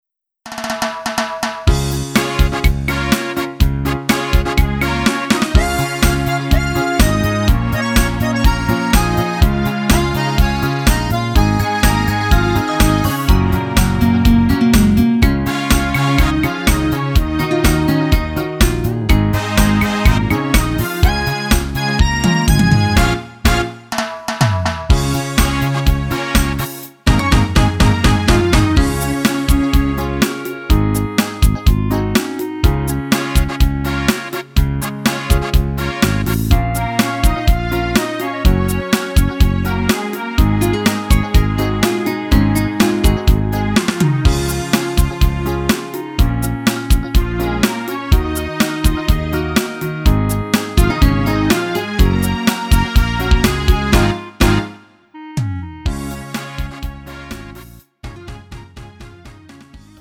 음정 원키 3:29
장르 가요 구분 Lite MR
Lite MR은 저렴한 가격에 간단한 연습이나 취미용으로 활용할 수 있는 가벼운 반주입니다.